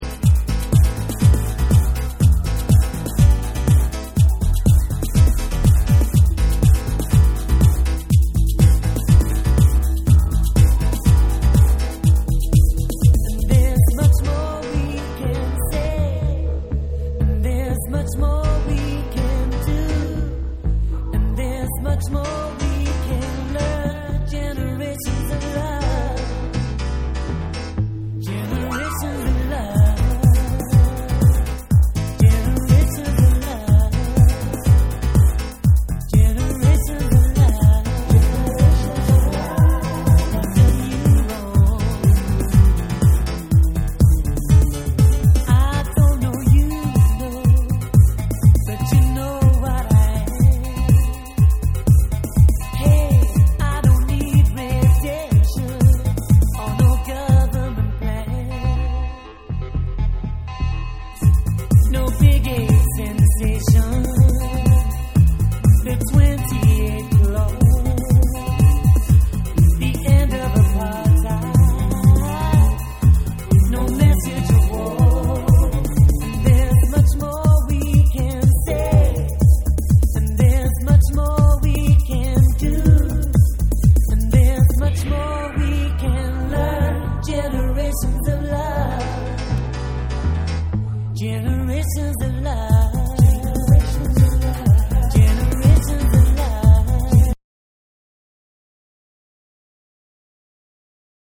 90年代初頭特有の鍵盤が弾けた
NEW WAVE & ROCK